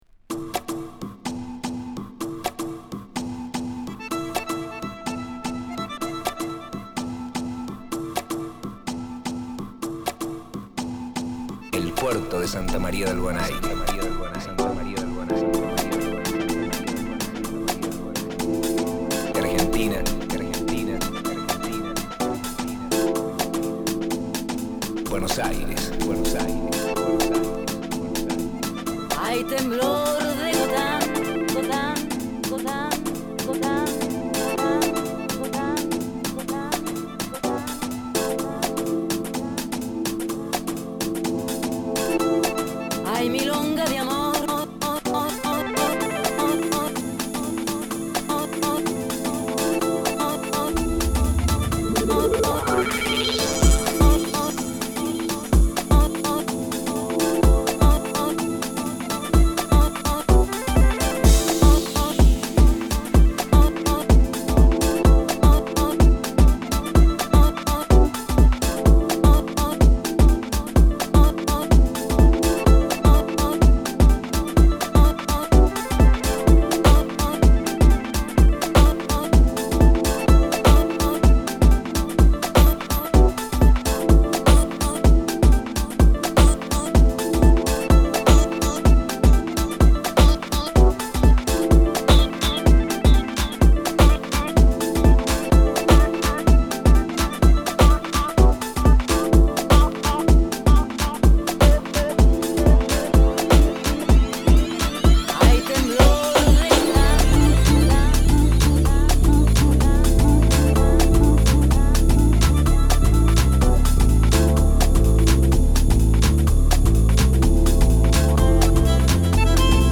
マイナーコードのアコーディオンに潰したサウンドのベースが奇妙なダンスグルーヴ。
」、c/wは、エコー感が心地よい涼しいディープ・ハウスヴァージョンを収録！！